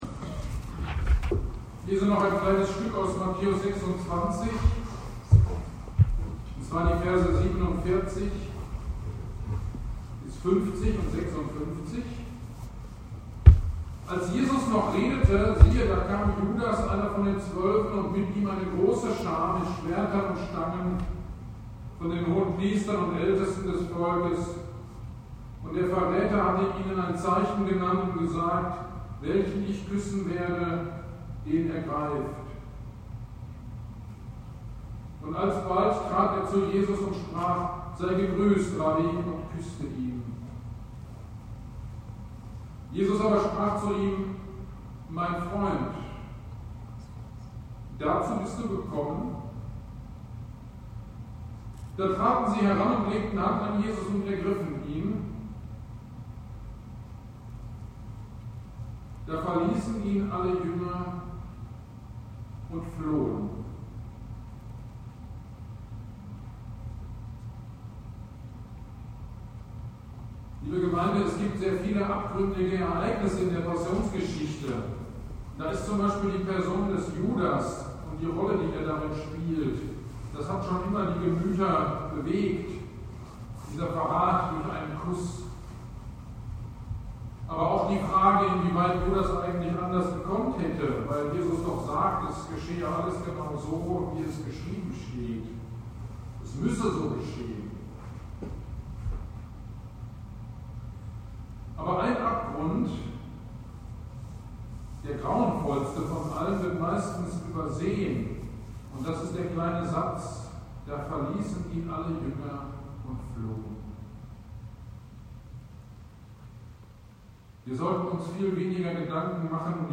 AGD am Gründonnerstag, Predigt zu Matthäus 26,56 - Kirchgemeinde Pölzig